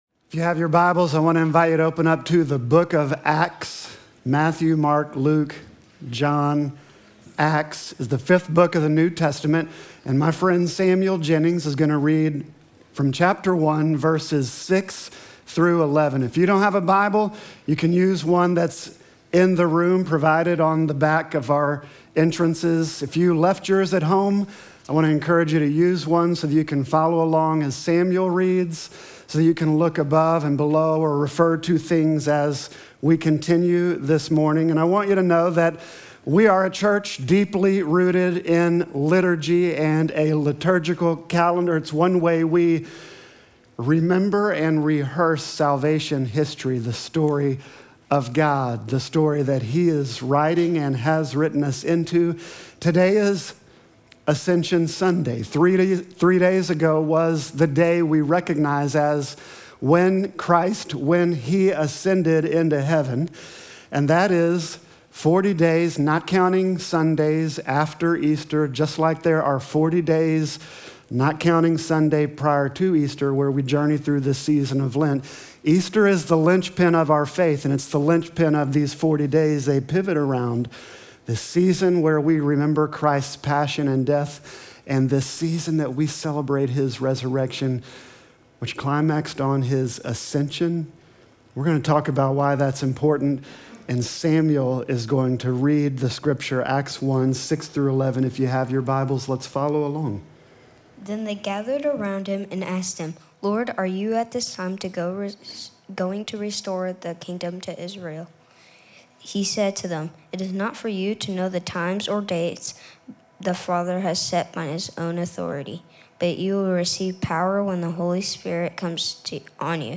Sermon text: Acts 1:6-11